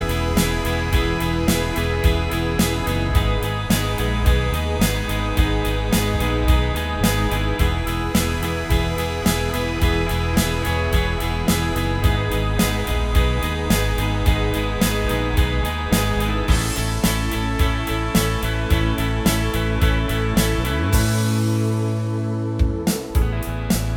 No Lead Guitar Pop